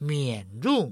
mienˆ rhungˋ
mienˇ rhungˋ